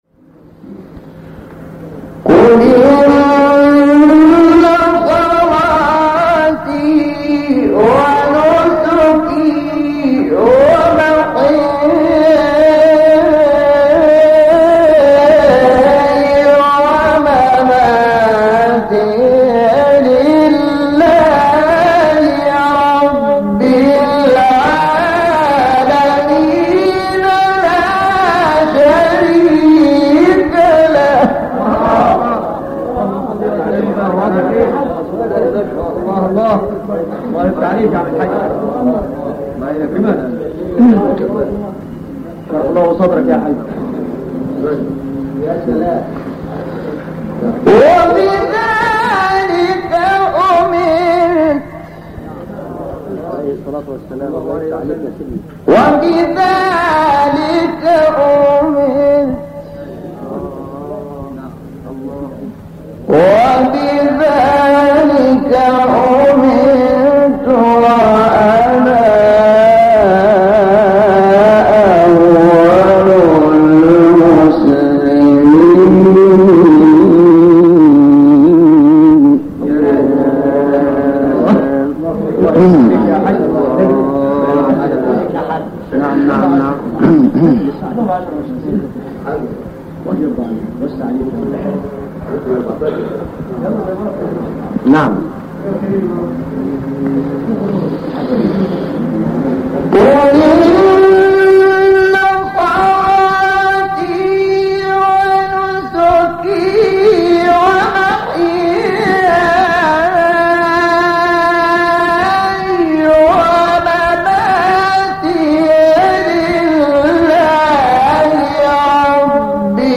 آیه 162-163 سوره انعام استاد شحات | نغمات قرآن | دانلود تلاوت قرآن